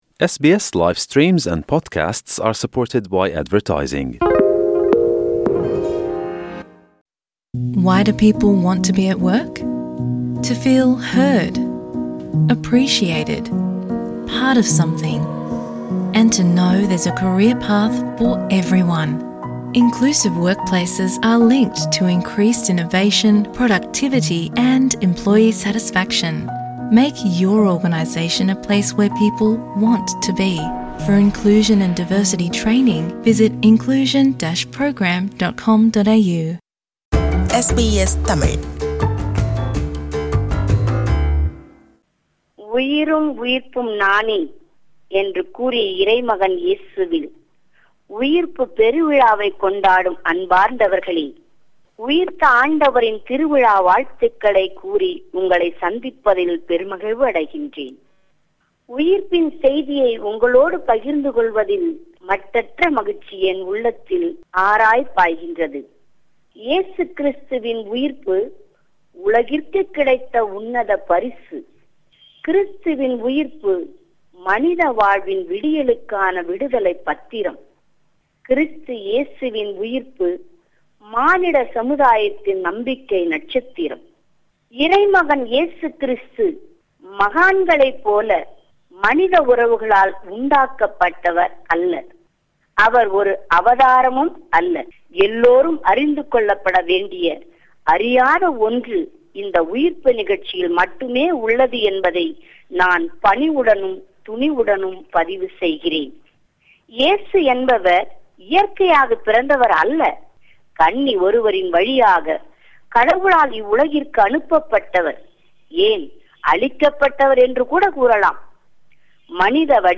ஈஸ்டர் - இயேசு உயிர்ப்பு நாள் சிறப்புரை.